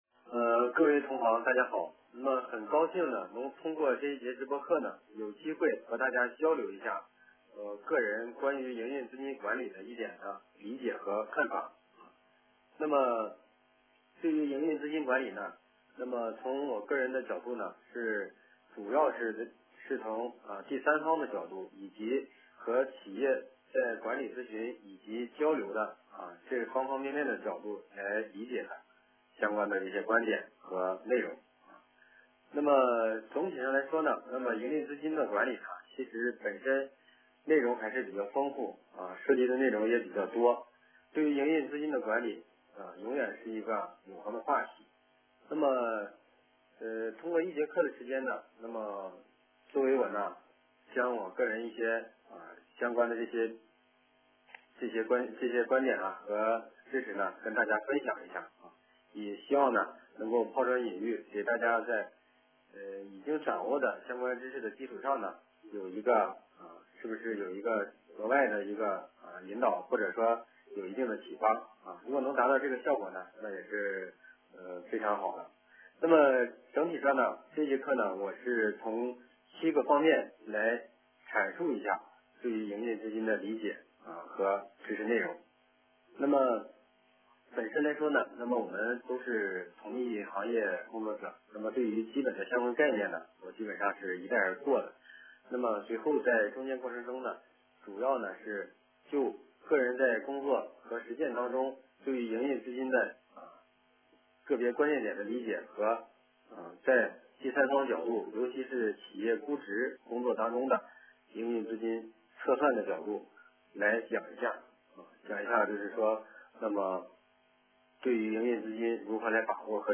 × 编辑会议备忘录 活动中将安排现场提问互动环节，如有任何内容希望与活动嘉宾交流，请将您的内容填写在以下方框中!
【电话会议】财务报表解读之营运资金管理